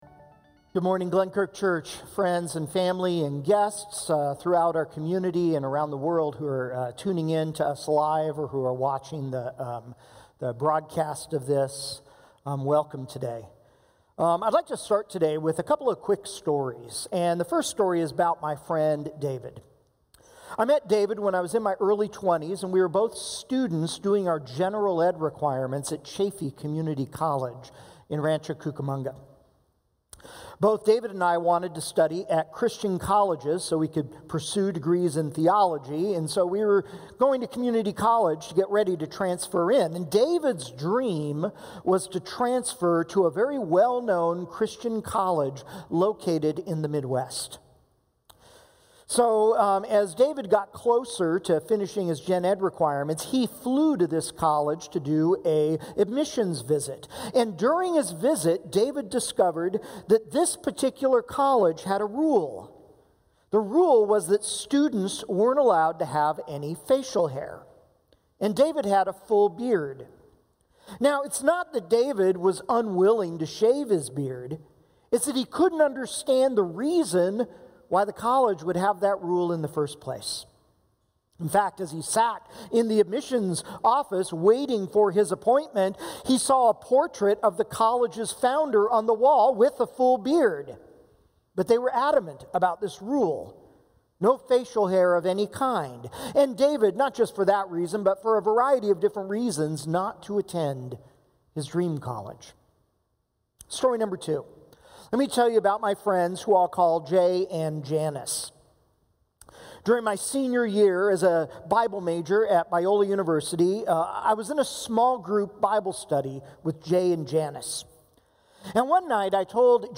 October 11, 2020 – Free to Be (Sermon Only) – Glenkirk Church